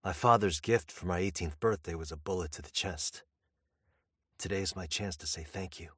Pocket voice line - My father's gift for my 18th birthday was a bullet to the chest. Today is my chance to say "thank you."